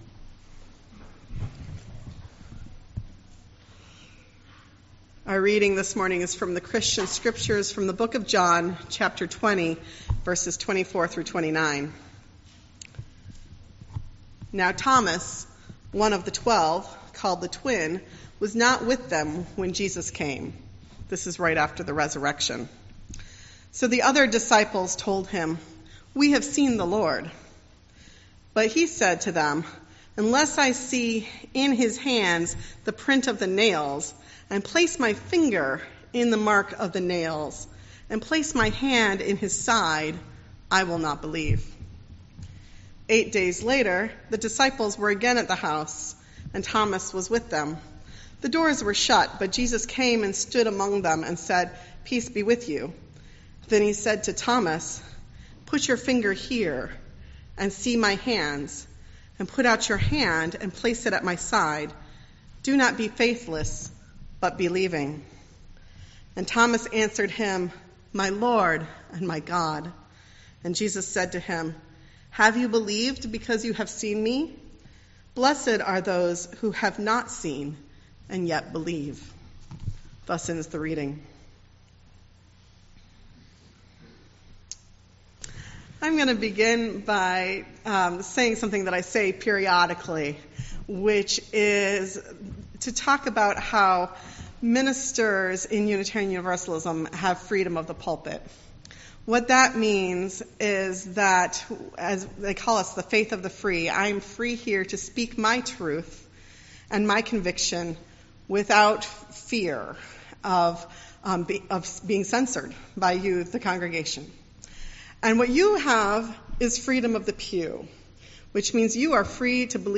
Our Unitarian-Universalist religious heritage compels us to address the important, widespread, and complex social issue of domestic violence. This special service, created and led by FPC's Domestic Violence Awareness Group, will focus on what is now a problem of epidemic proportions in our country and in our communities.